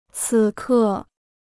此刻 (cǐ kè) พจนานุกรมจีนฟรี